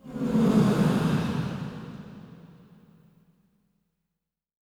SIGHS 2   -R.wav